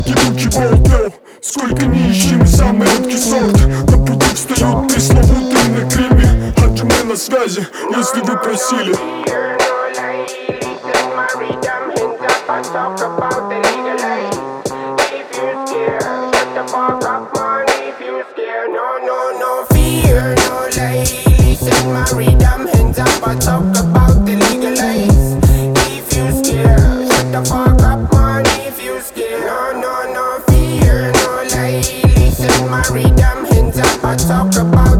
Жанр: Рэп и хип-хоп / Русские
# Old School Rap